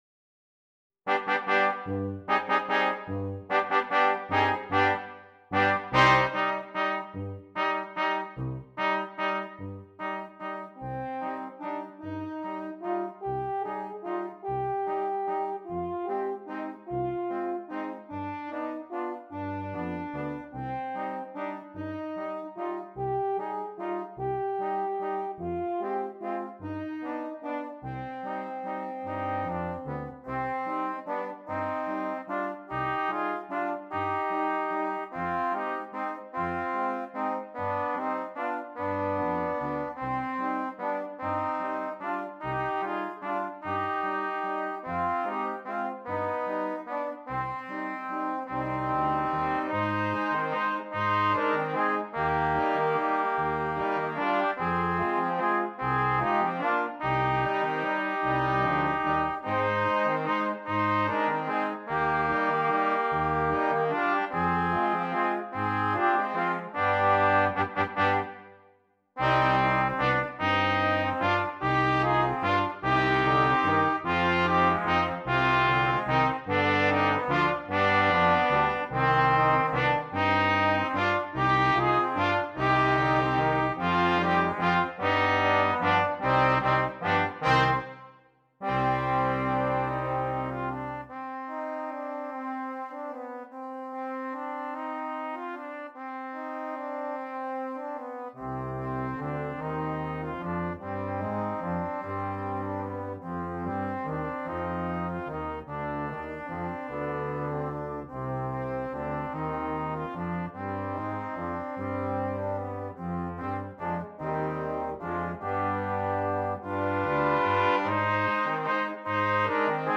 Brass Quintet
Set in a minor mode and using a waltz metre